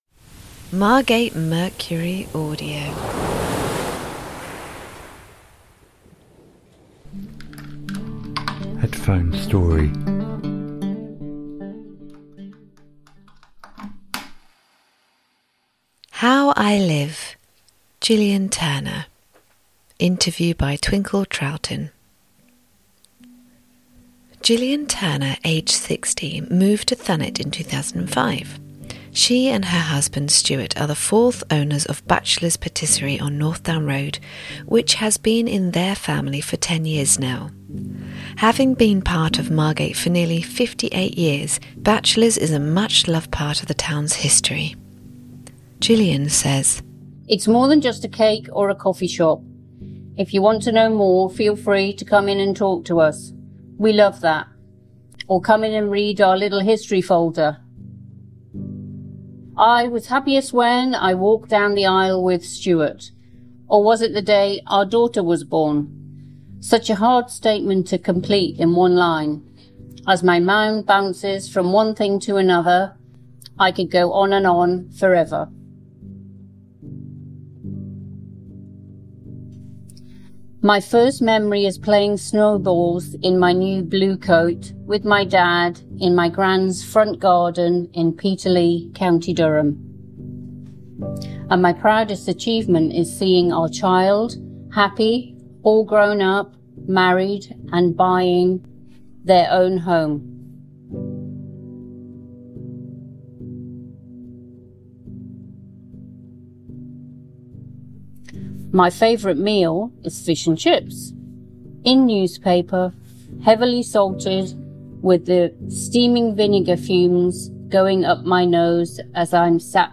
Interview by